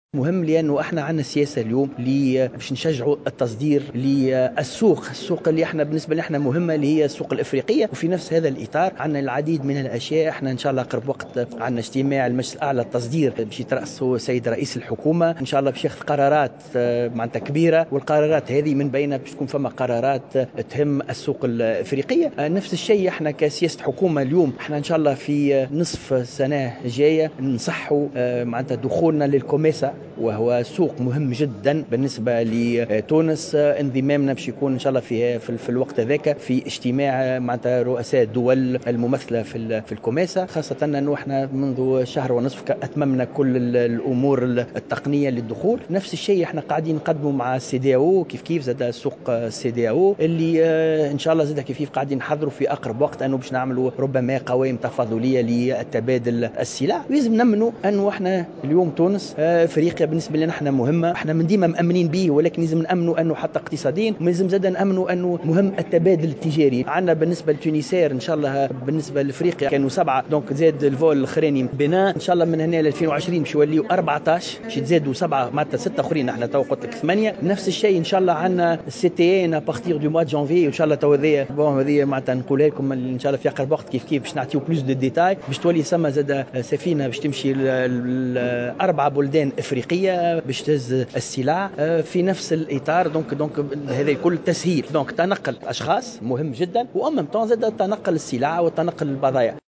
أكد كاتب الدولة لدى وزير التجارة مكلف بالتجارة الخارجية هشام بن أحمد في تصريح للجوهرة "اف ام" على هامش ملتقى للتعريف بالصالون الافريقي الأول للبناء "أفريبات" أن هناك سياسة كاملة لتشجيع التصدير للسوق الإفريقية.